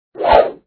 14_swing.mp3